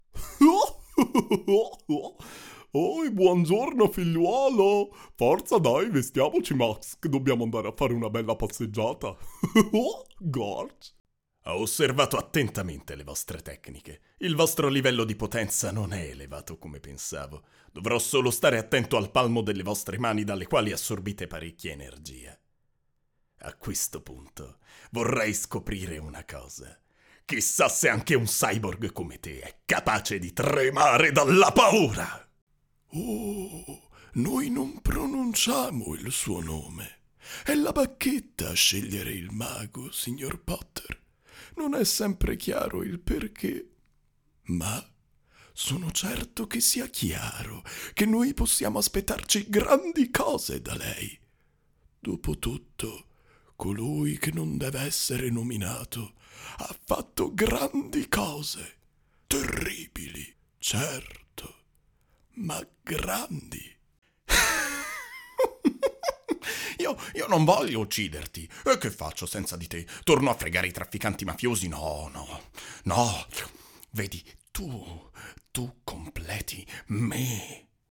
Young voice actor with experience in dubbing.